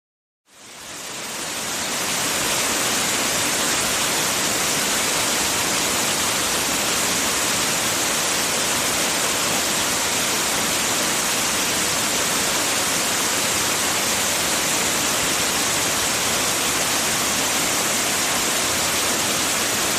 Free Nature sound effect: Pond Frogs.
Pond Frogs
472_pond_frogs.mp3